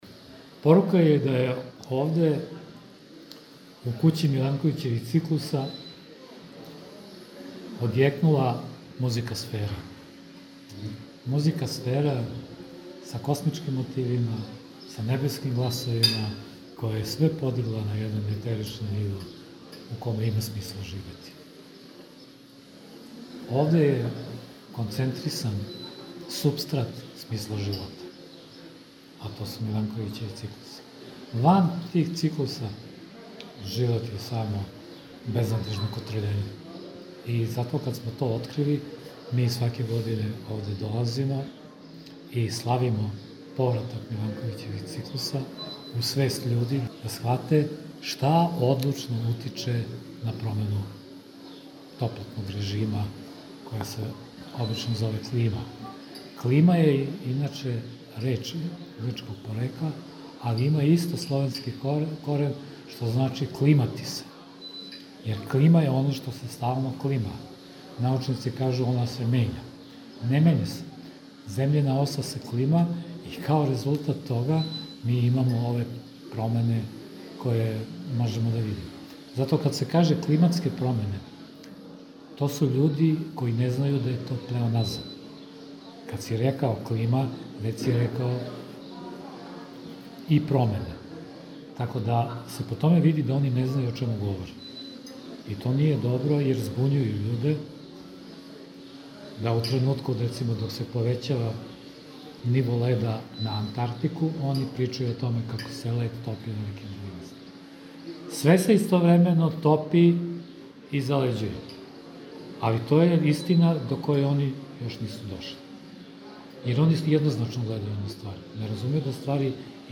током трајања манифестације упутио је поруку из Даља: